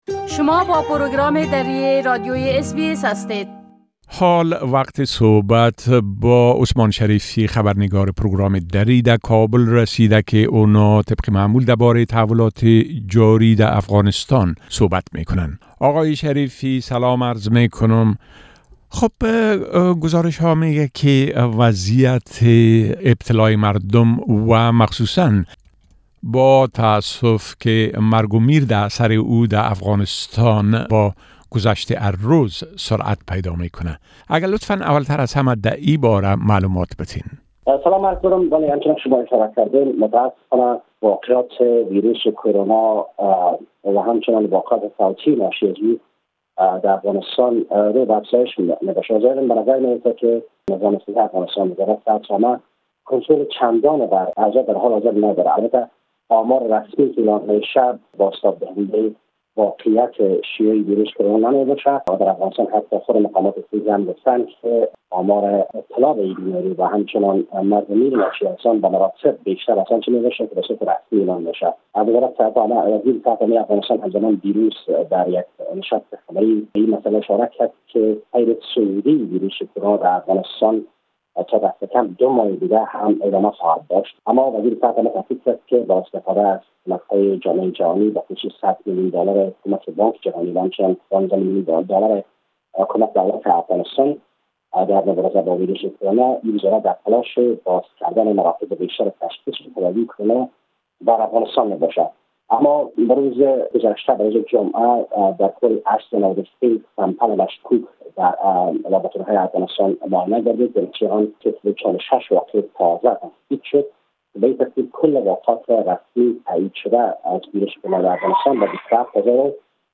گزارش كامل خبرنگار ما در كابل بشمول اوضاع امنيتى٬ و تحولات مهم ديگر در افغانستان را در اينجا شنيده ميتوانيد.